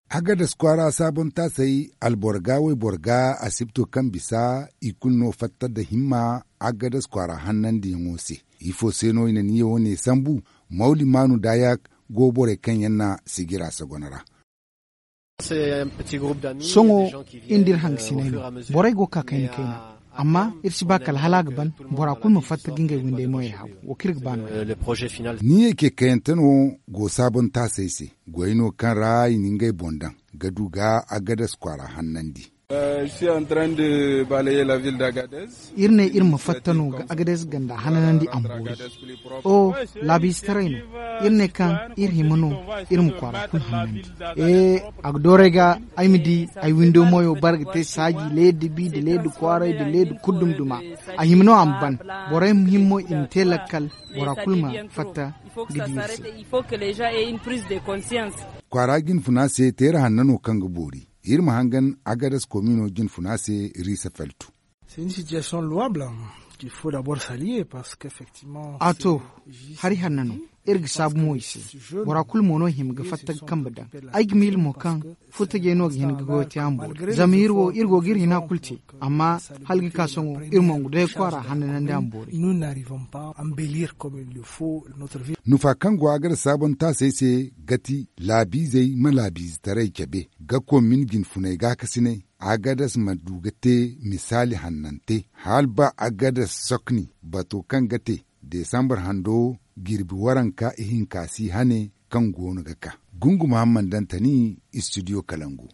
Magazine en français.